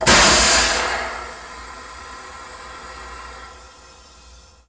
Airlock Door Open, Burst Of Steam With Hiss Fade Out